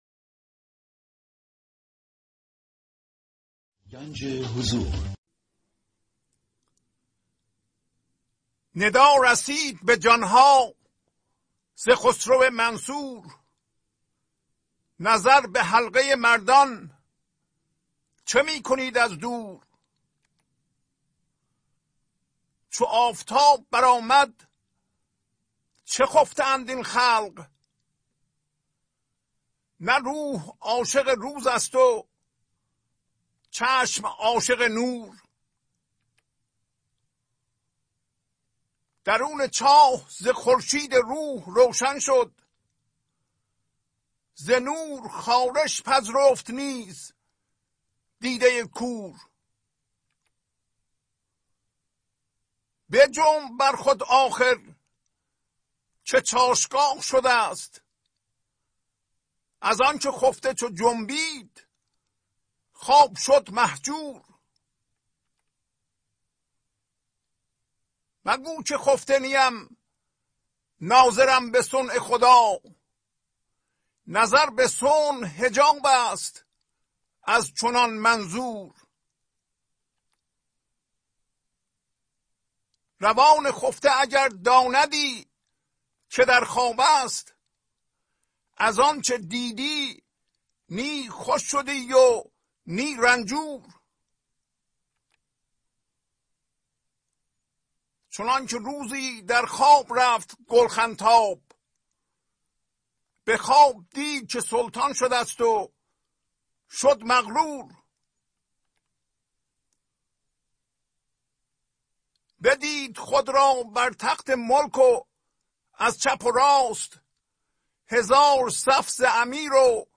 خوانش تمام ابیات این برنامه - فایل صوتی
944-Poems-Voice.mp3